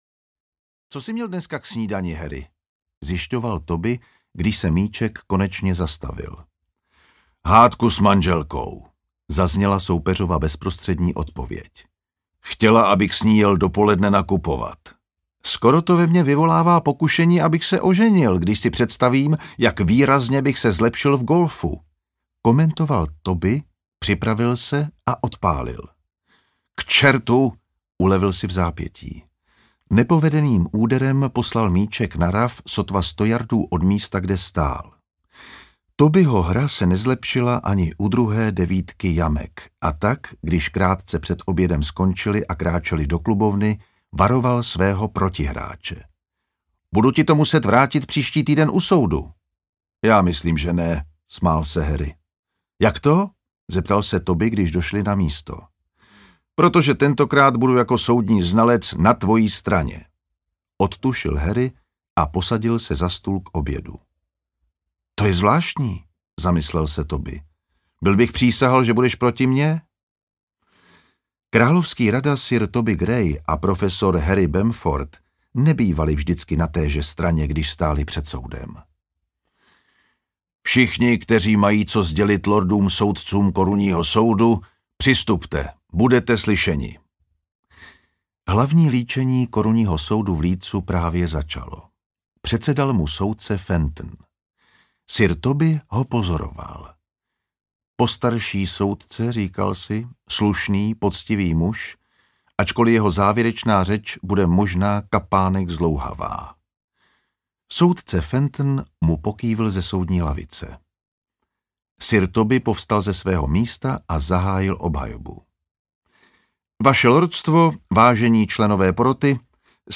Audio knihaAbych to zkrátil
Ukázka z knihy
• InterpretJan Vondrácek